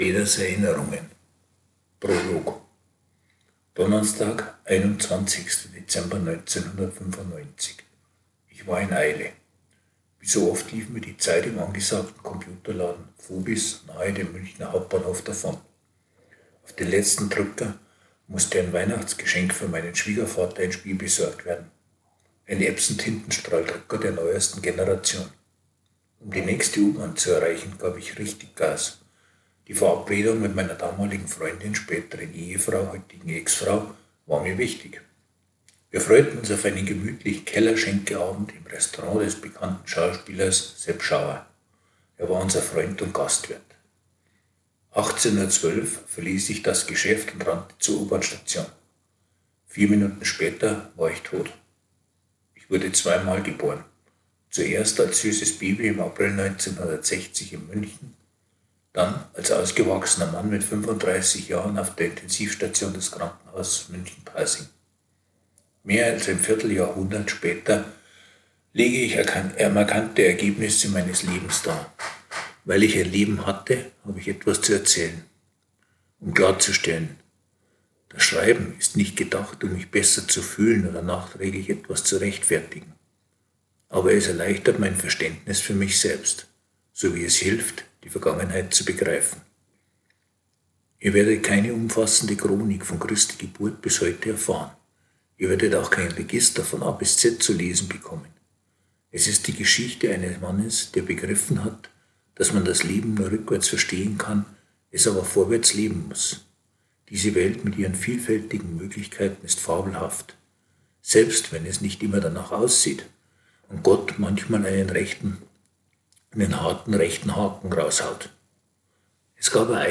Beitrag vorlesen (4 Minuten)